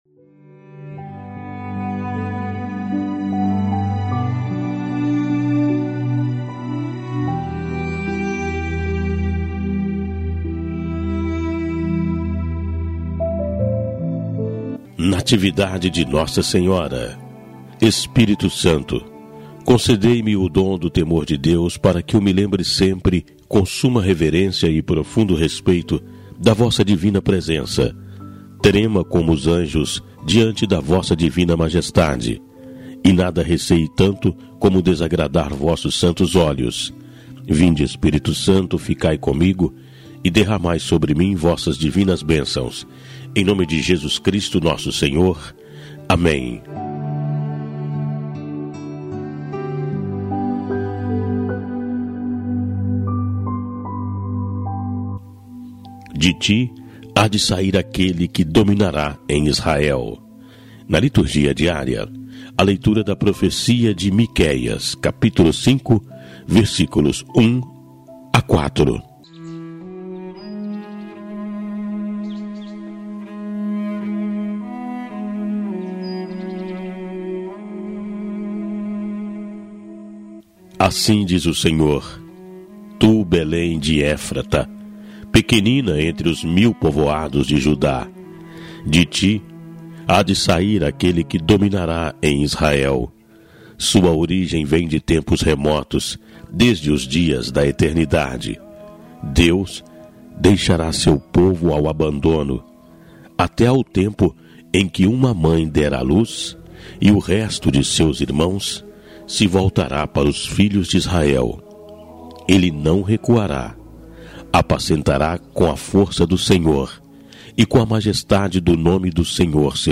Leitura do Profeta Miquéias